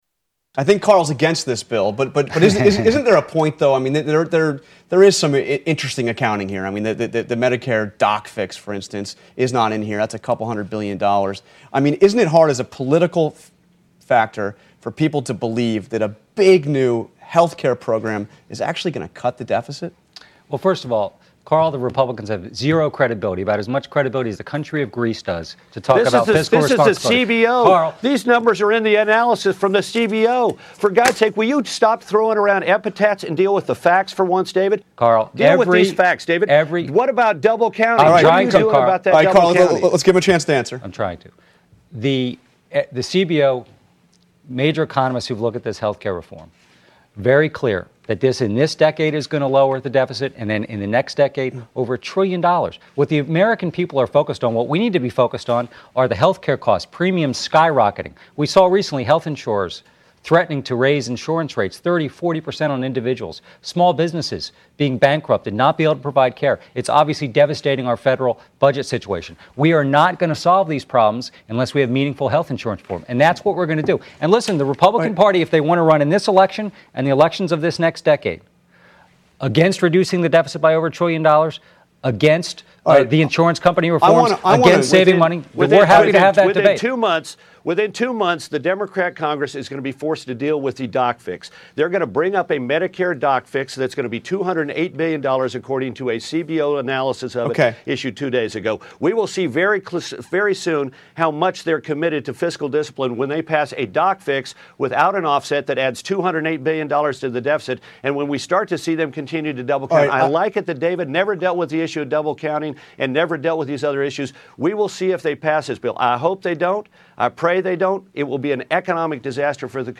Karl Rove and David Plouffe Debate prt 2